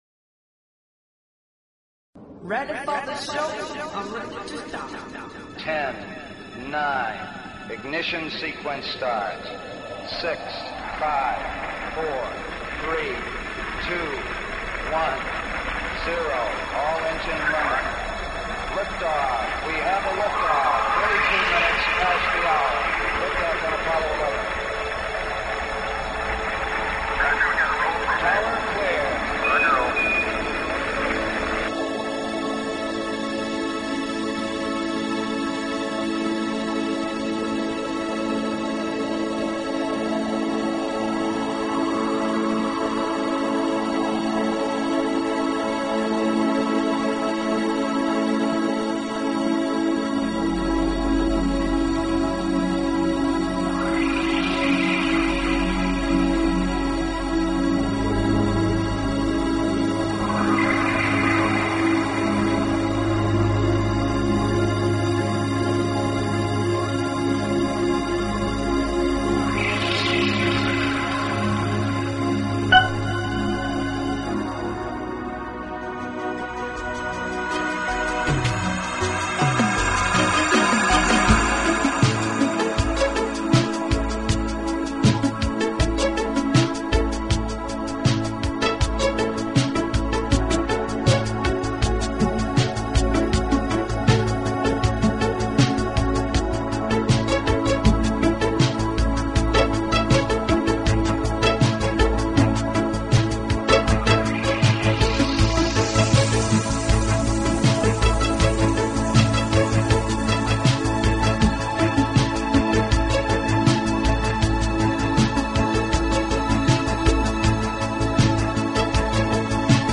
Talk Show Episode, Audio Podcast, ET-First_Contact_Radio and Courtesy of BBS Radio on , show guests , about , categorized as
Our voices are altered for his safety